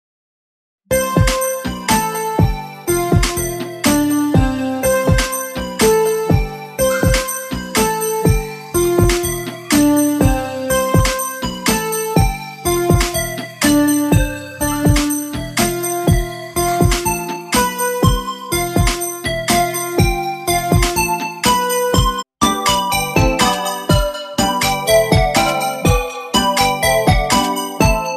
Christmas Ringtones